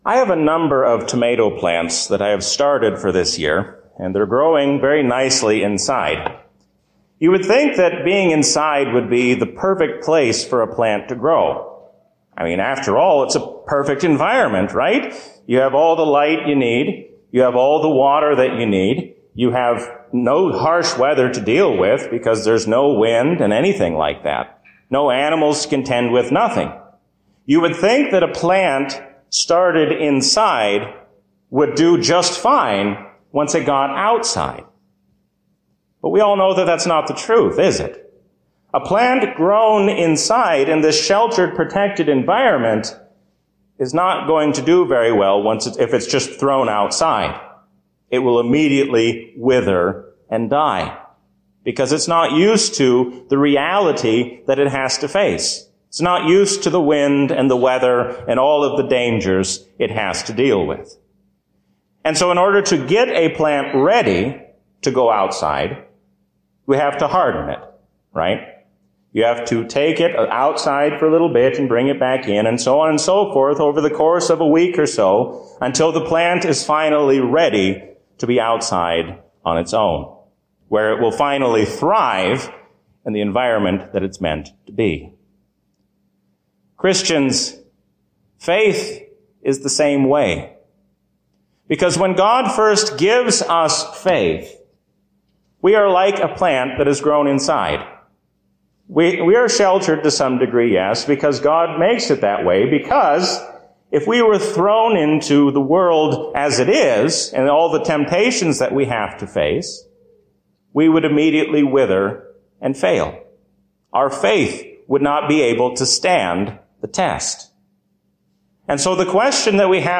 A sermon from the season "Trinity 2022." God is your loving Father and gives you the strength to face all of life's troubles.